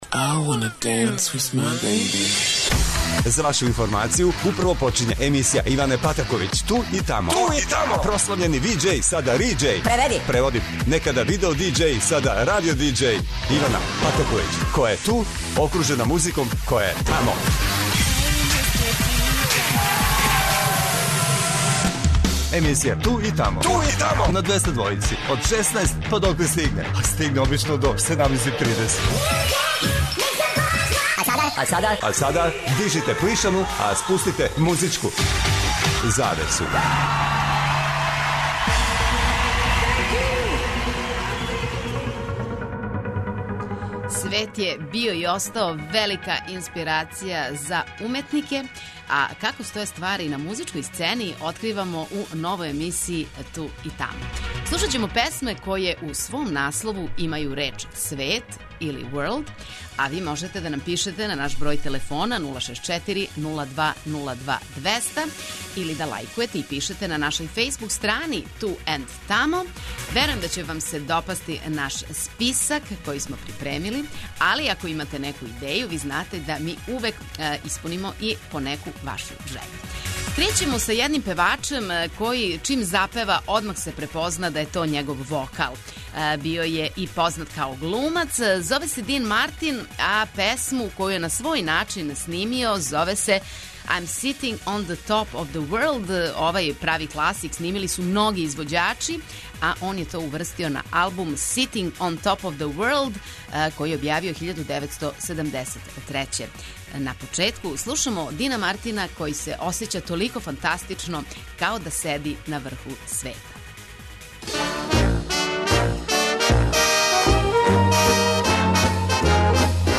Ове суботе, емисија „Ту и тамо“ открива везу између „света“ и музике што значи да ће се на Двестадвојци завртети велики хитови који у свом наслову имају реч „свет“.